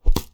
Close Combat Attack Sound 27.wav